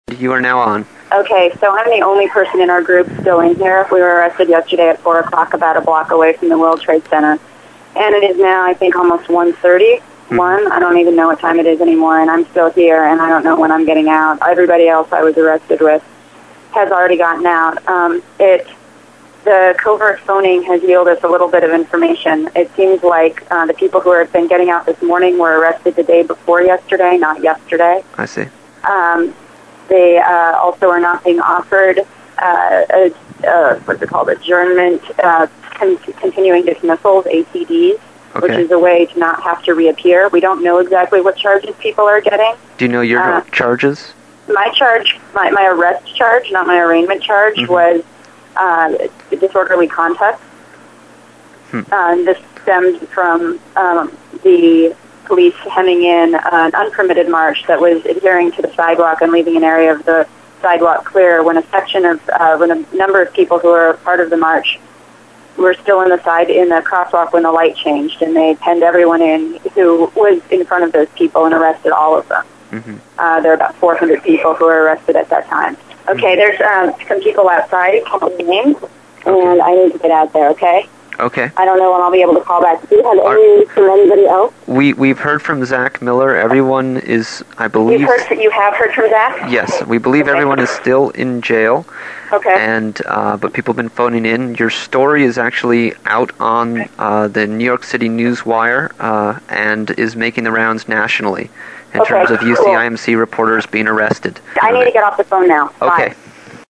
UCIMC Reporter Phone-In from Jail